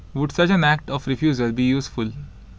Phonetically Rich Audio Visual (PRAV) corpus
a2302_M1.wav